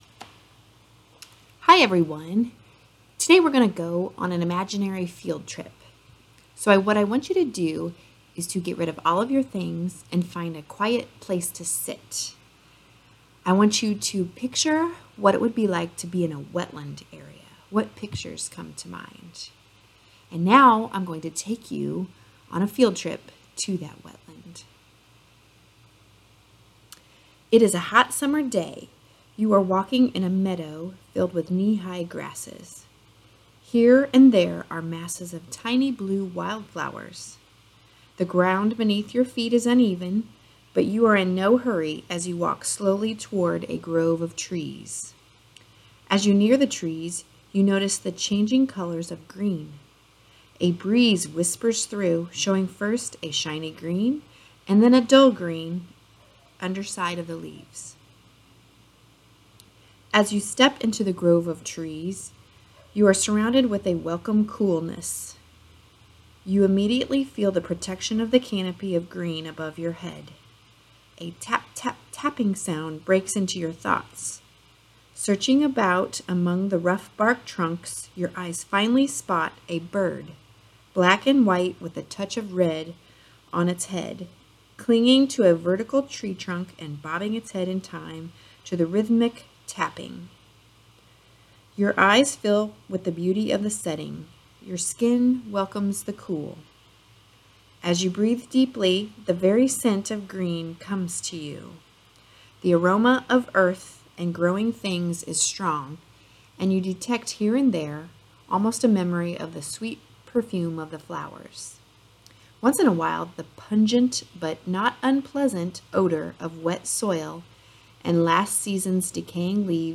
Wetland Imaginary Field Trip: The audio story below takes you on an imaginary field trip.  This is a slow-down meditation activity and will relax and refresh your mind.
Imaginary-Wetland-Field-Trip2.m4a